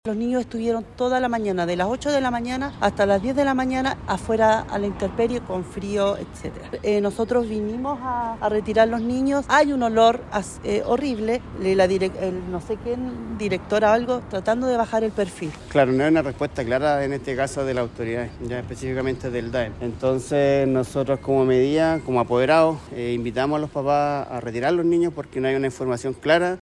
En conversación con Radio Bío Bío, algunos de ellos visiblemente molestos, apuntaron directamente a la dirección y al DAEM por no solucionar este inconveniente.